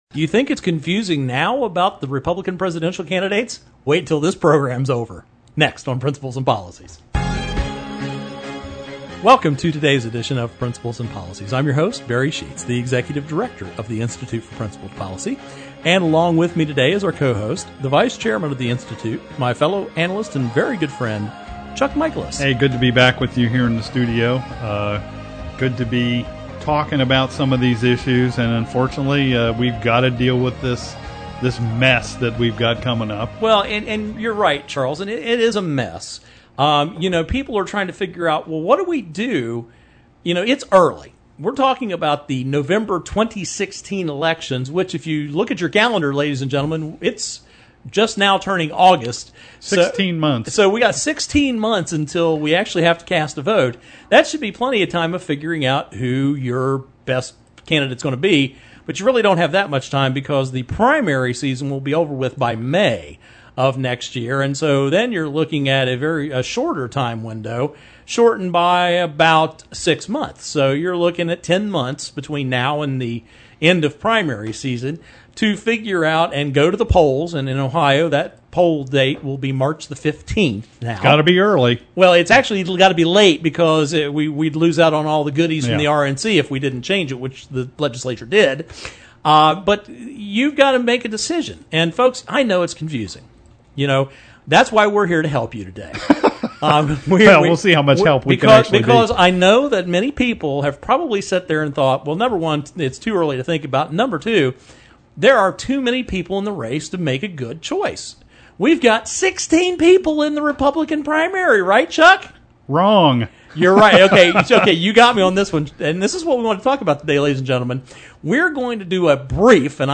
Our Principles and Policies radio show for Saturday August 1, 2015.